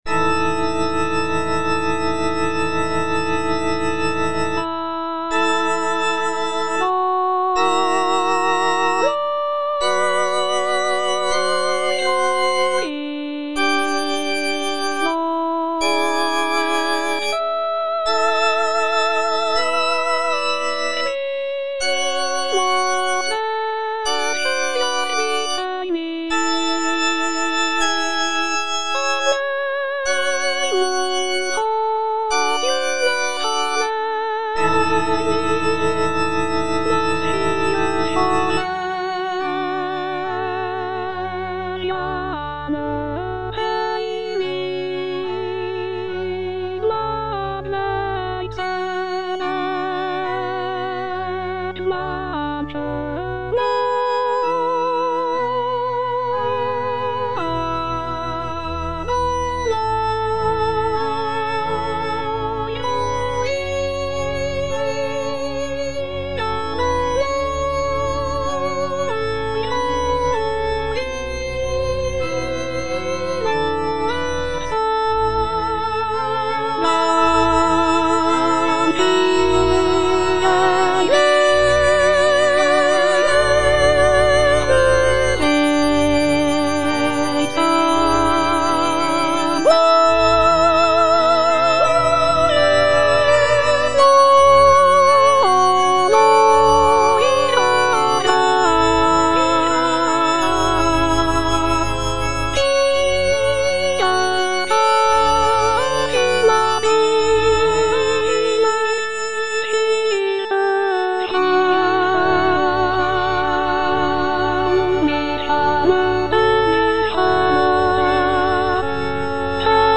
(soprano I) (Emphasised voice and other voices) Ads stop